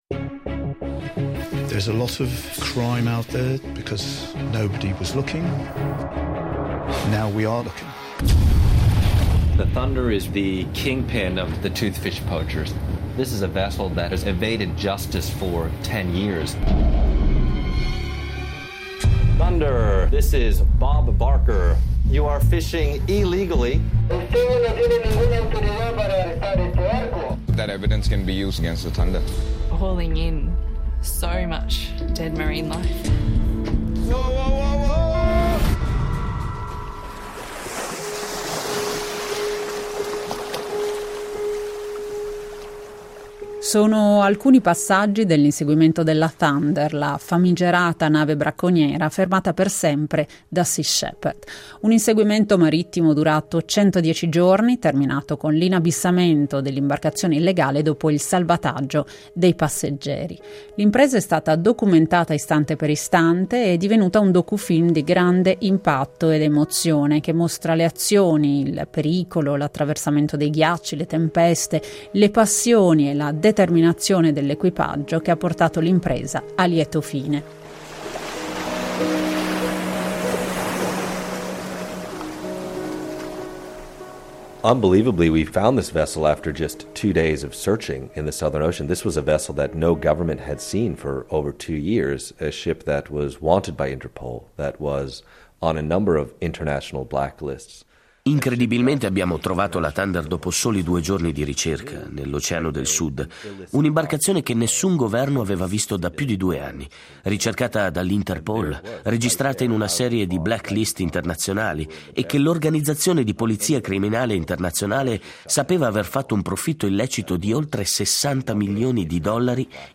vi riproponiamo l'ascolto di un documentario dedicato proprio a questo gigantesco traffico internazionale